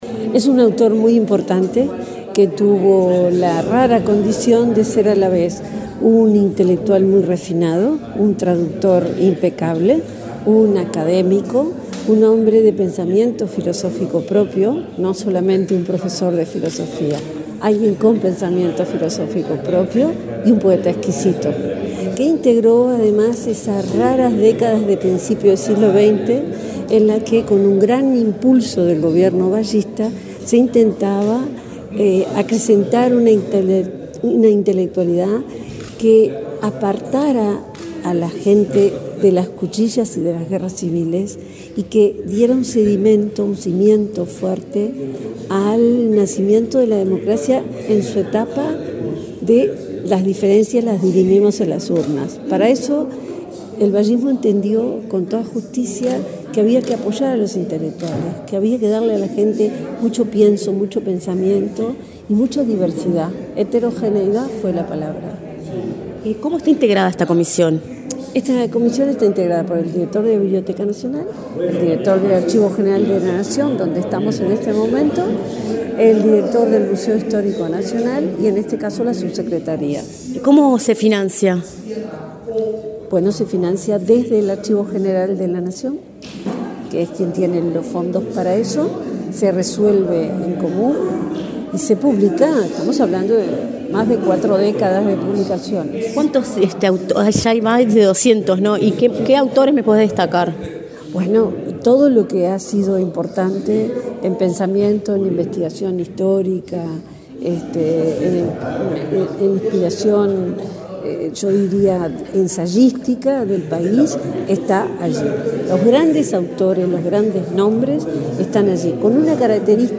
Declaraciones de la subsecretaria de Educación y Cultura, Ana Ribeiro
La subsecretaria de Educación y Cultura, Ana Ribeiro, dialogó con Comunicación Presidencial, luego de participar en la presentación de la edición del